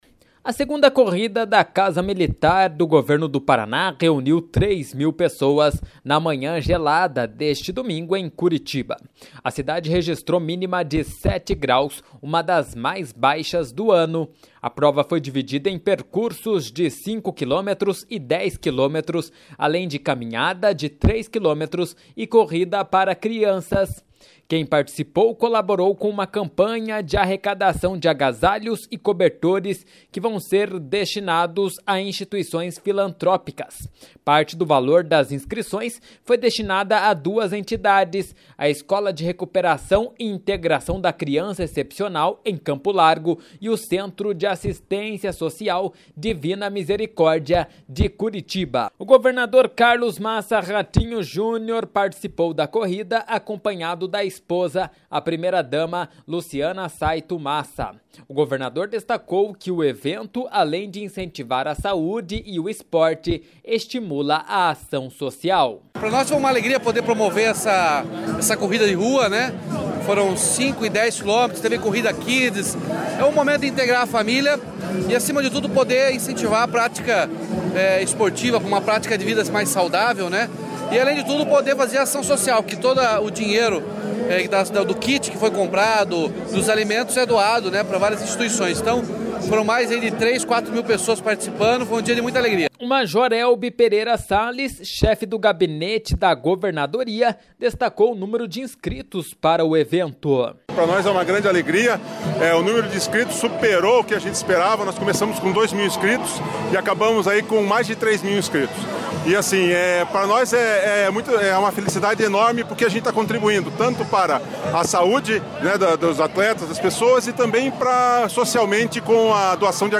// SONORA RATINHO JUNIOR.// O major Welby Pereira Sales, chefe do Gabinete Militar da Governadoria, destacou o número de inscritos para o evento.// SONORA WELBY PEREIRA SALES.// Além do governador e do chefe do Gabinete Militar, o chefe da Casa Civil, Guto Silva, também participou da corrida.